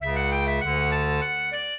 minuet2-5.wav